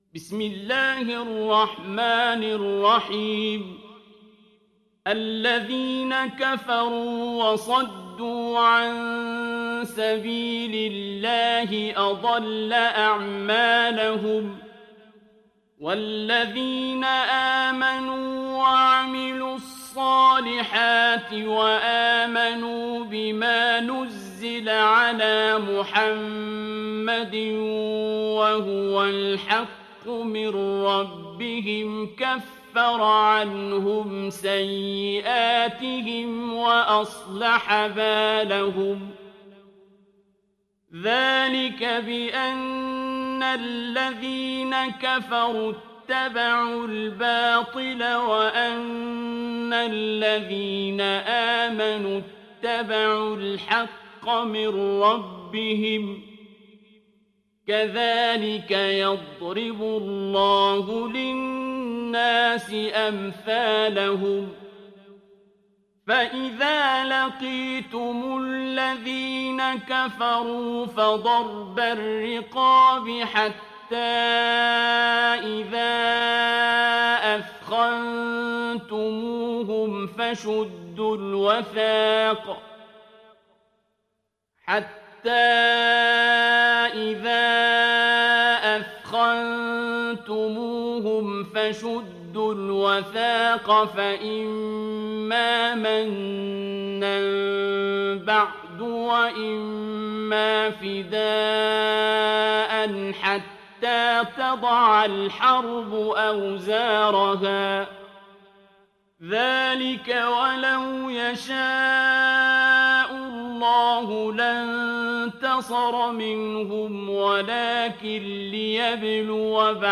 دانلود سوره محمد mp3 عبد الباسط عبد الصمد (روایت حفص)